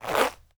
zipper sounds for opening backpacks
zip_in.wav